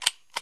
Free MP3 firearms sound effects 5